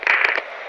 RadioOn.ogg